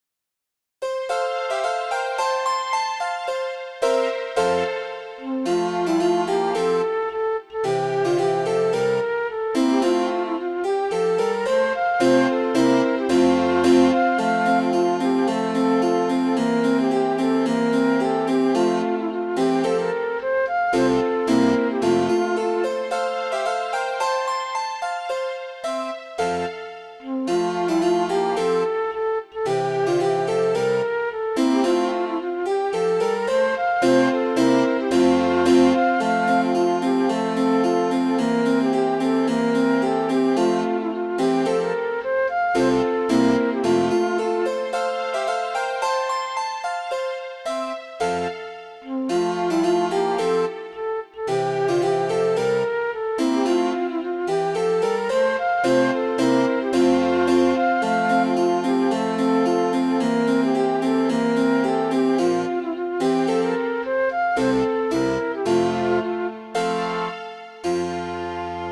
Midi File, Lyrics and Information to The Time I've Lost in Wooing
wooing.mid.ogg